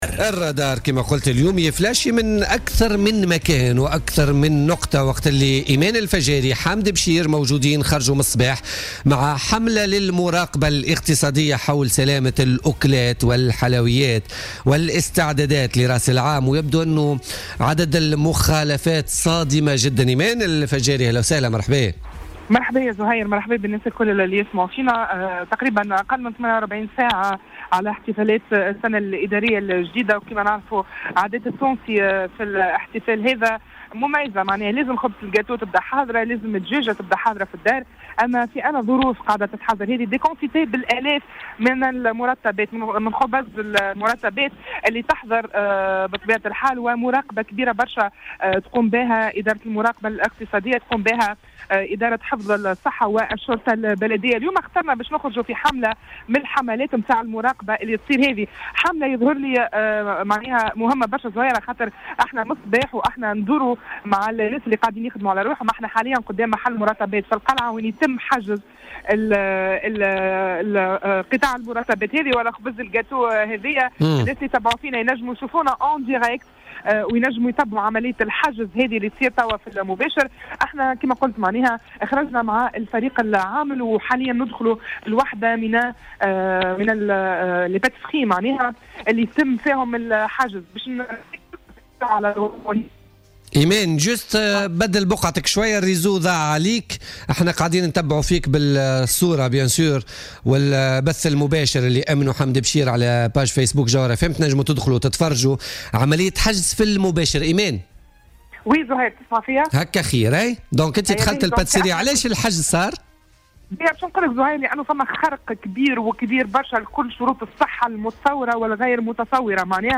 واكب الرادار اليوم الخميس 29 ديسمبر 2016 حملة مشتركة بين المراقبة الإقتصادية و الشرطة البلدية و حفظ الصحة في القلعة الكبرى " يومان قبل الإحتفال بالسنة الإدارية 2017 الوضع كارثي".